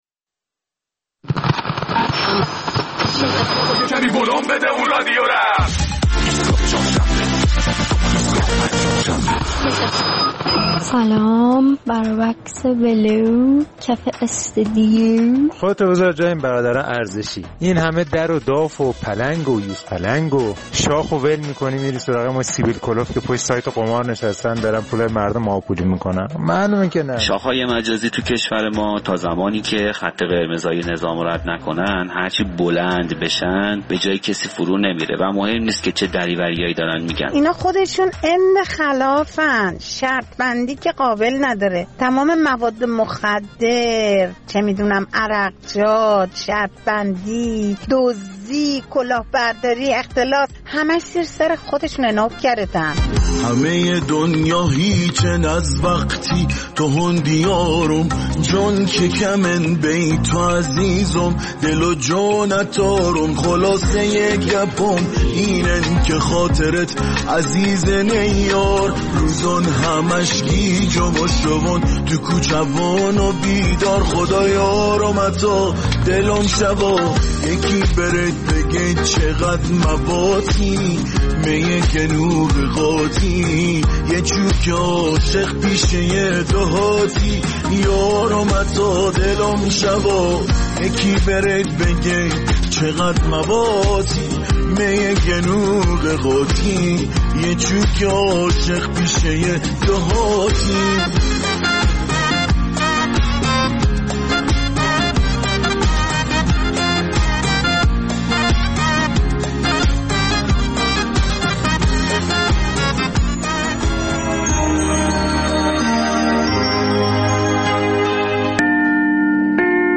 در این برنامه نظرات شنوندگان برنامه را در مورد تصمیم جدید پلیس فتا برای برخورد با شاخ‌های مجازی و صفحات پرطرفدار می‌شنویم.